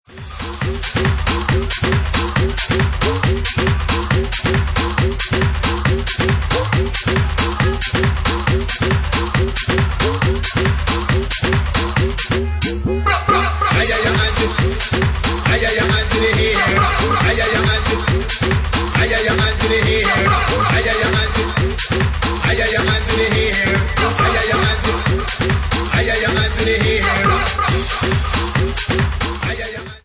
Bassline House at 69 bpm